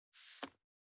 436物を置く,バックを置く,荷物を置く,トン,コト,ドサ,ストン,ガチャ,ポン,タン,スッ,ゴト,カチャ,
効果音室内物を置く